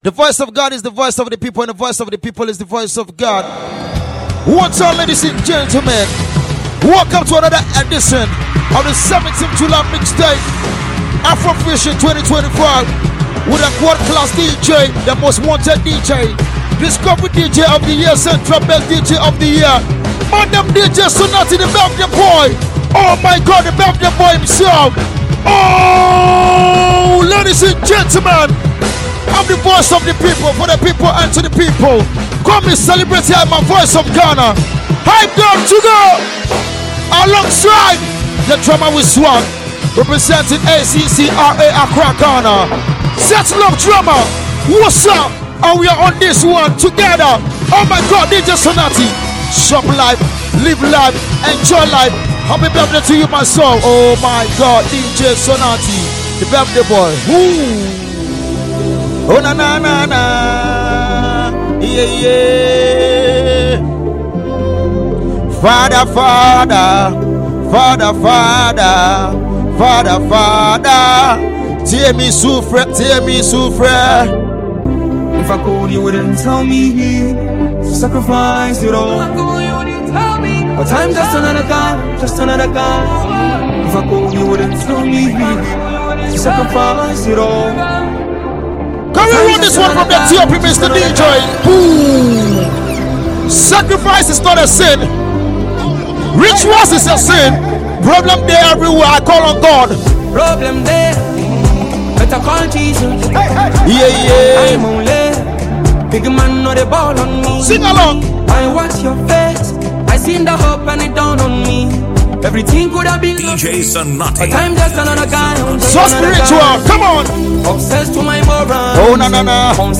Enjoy this well-produced mix
a super-talented Ghanaian disc jockey.
mixtape